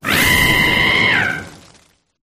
cinderace_ambient.ogg